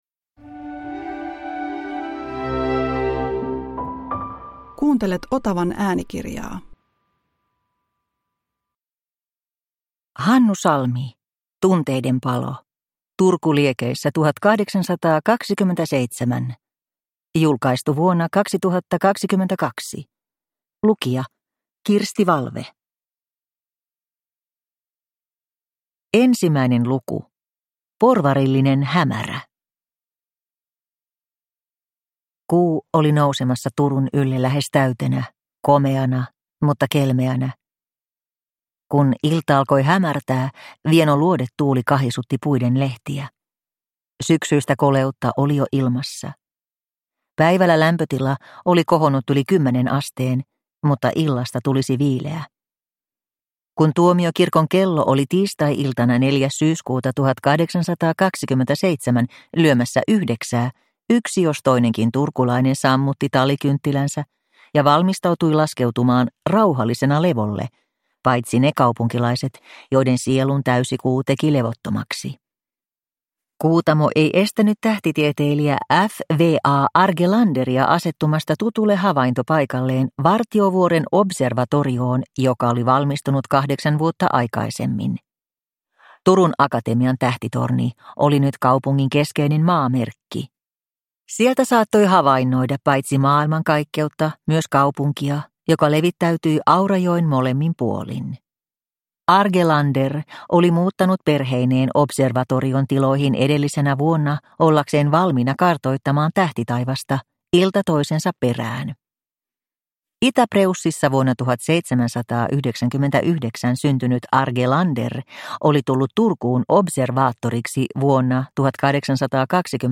Tunteiden palo – Ljudbok – Laddas ner